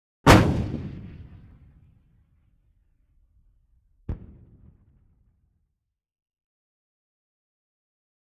These sound effects were purchased from The Hollywood Edge under a license allowing our classroom and research use.
Or something a little burlier, suitable as a wakeup call.
Howitzer Artillery Cannon: Fire; Sharp Metallic Sounding Missile
Launch With Short Echo Followed By Distant Explosion When Missile
Hits Its Target. Good Outgoing Missile. Launch Is Very Close Up.